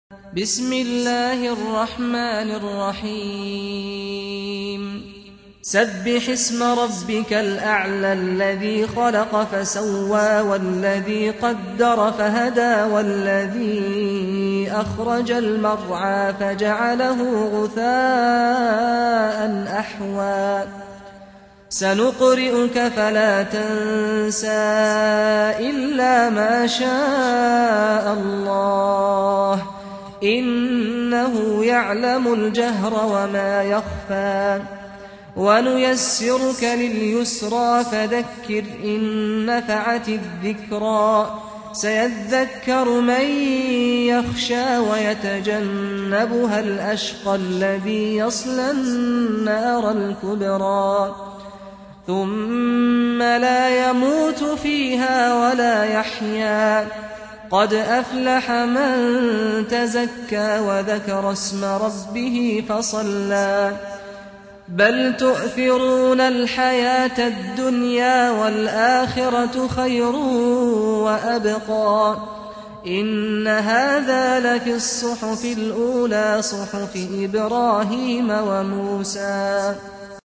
سُورَةُ الأَعۡلَىٰ بصوت الشيخ سعد الغامدي